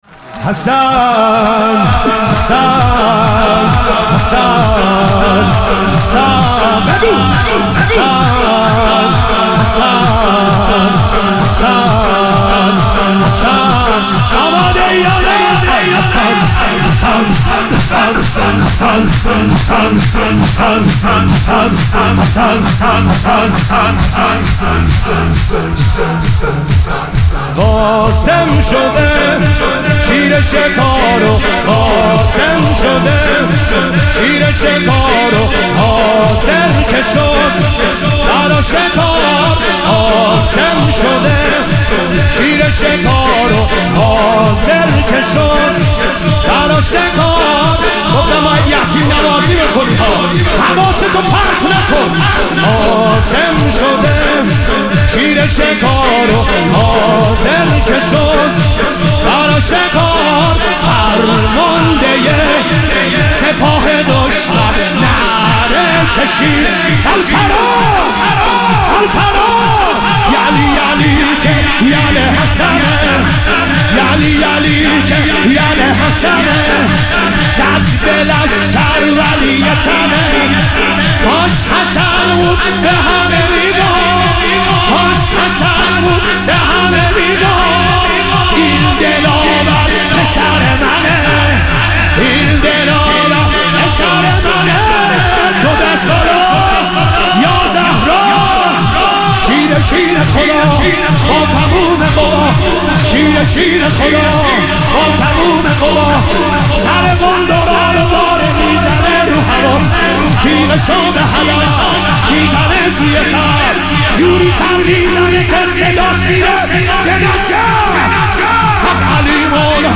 مشهد الرضا - شور